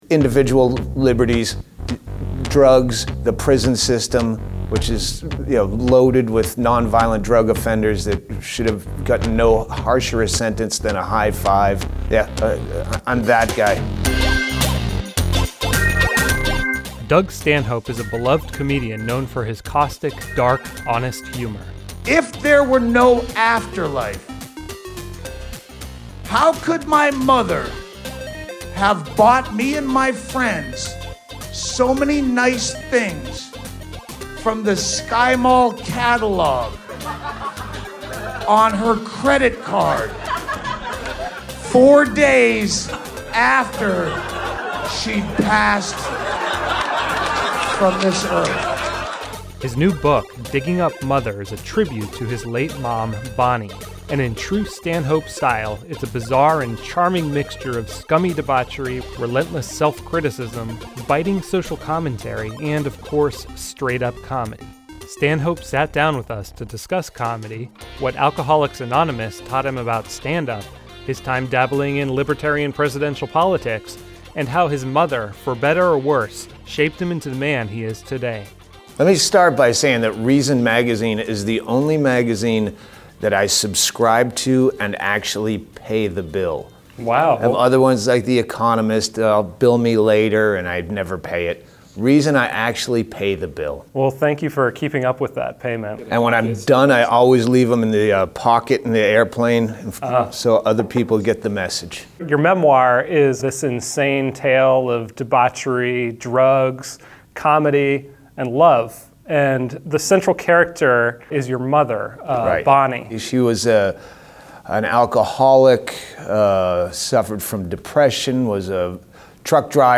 The comedian sat down with Reason TV to discuss his new book "Digging Up Mother."